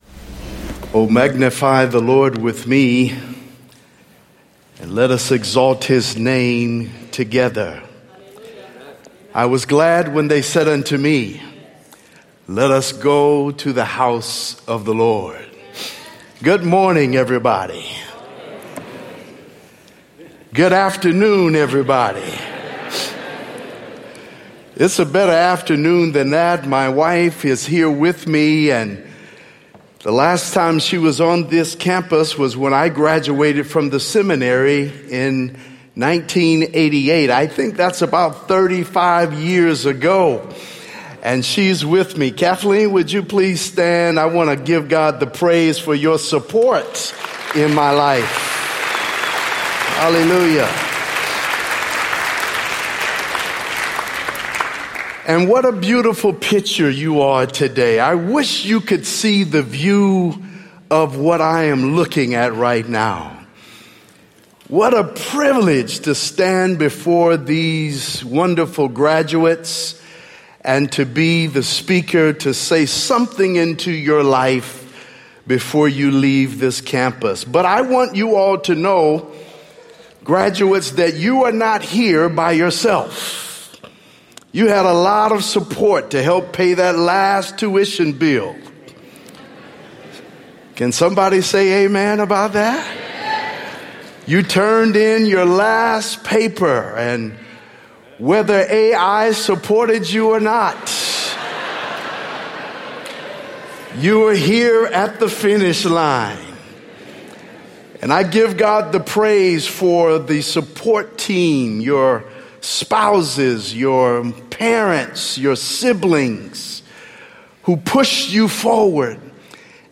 BACCALAUREATE SERMON